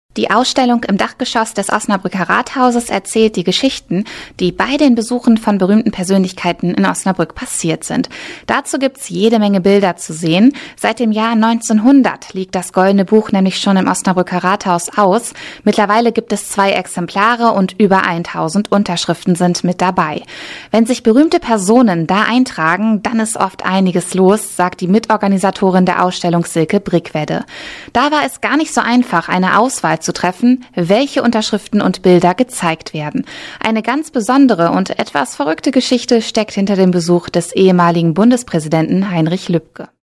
Sprecherin, Werbesprecherin, Moderatorin